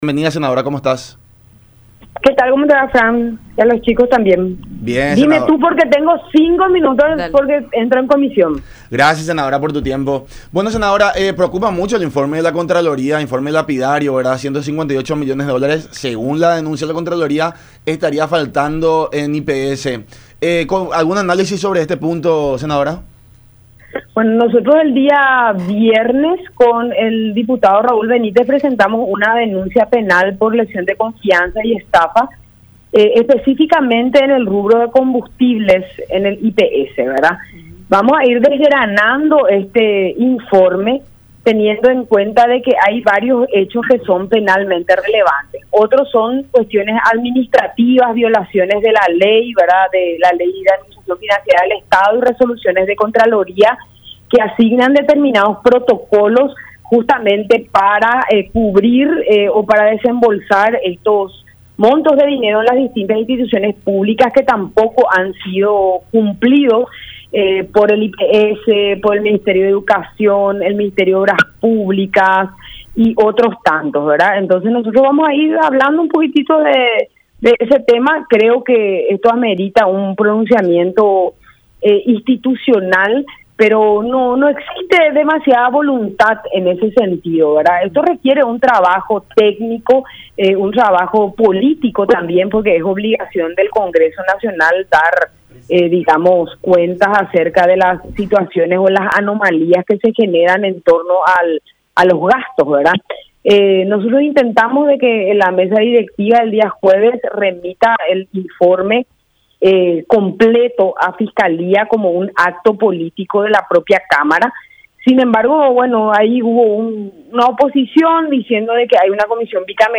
“Nosotros intentamos que la mesa directiva del día jueves remita el informe completo a fiscalía , sin embrago hubo una oposición”, agregó en el programa “La Unión Hace La Fuerza” por Unión TV y radio La Unión.